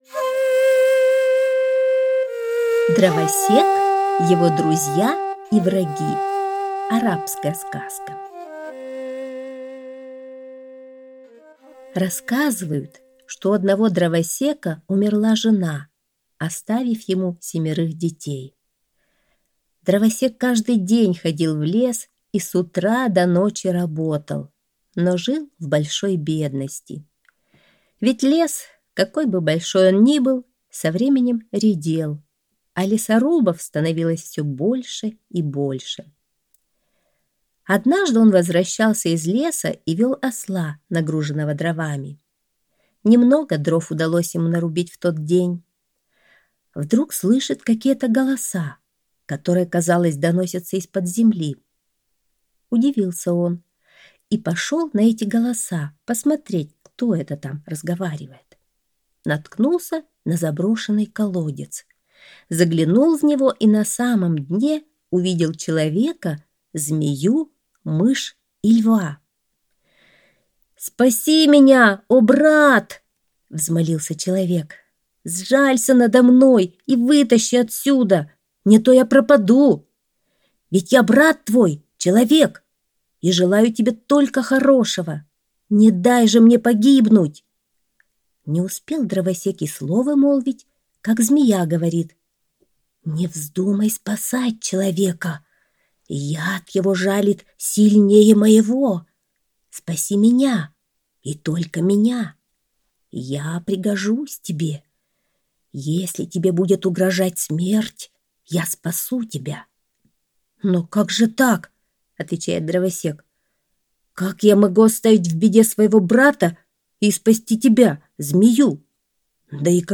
Дровосек, его друзья и враги - арабская аудиосказка - слушать